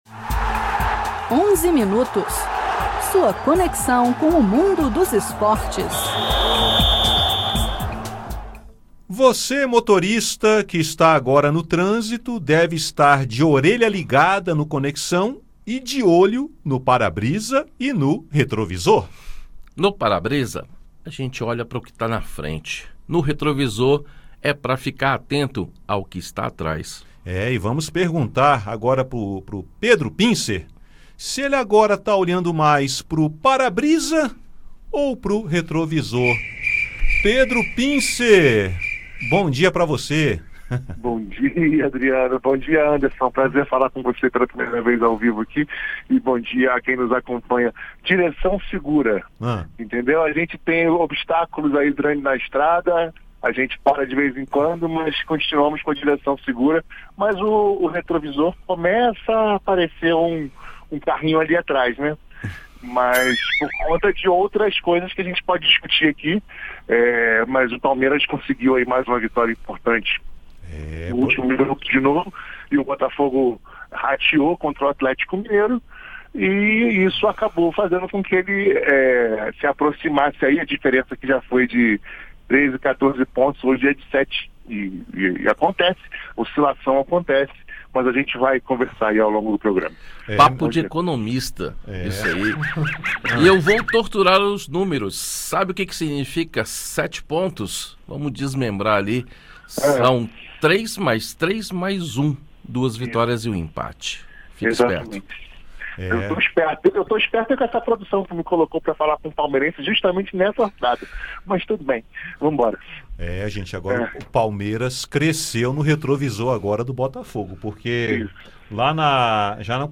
Nos comentários esportivos, as últimas do pré-olímpico de vôlei feminino, a rodada do Brasileirão e a Copa do Brasil.